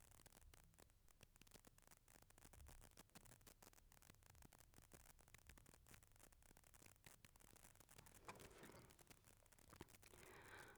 В дополнение: от чего появляется периодически такой звуковой фон? В обычных колонках почти не слышно, а в наушниках прям бьёт по ушам.